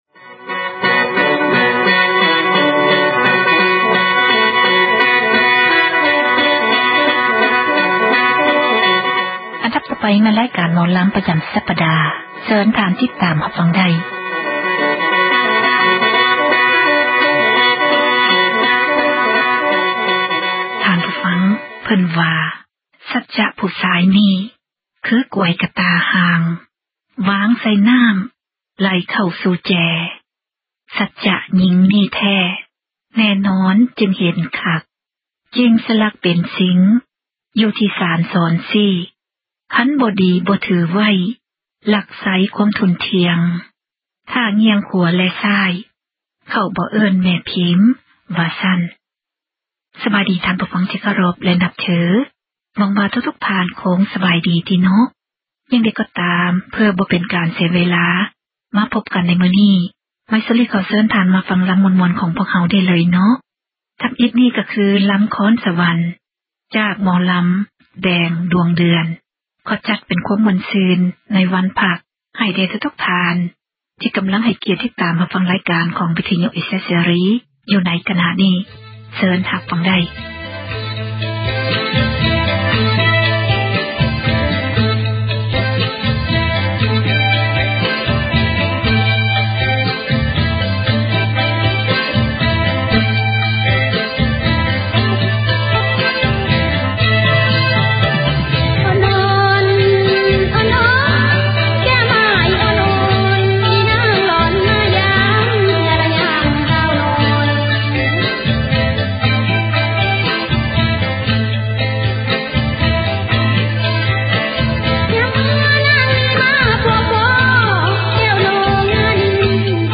ໝໍລໍາ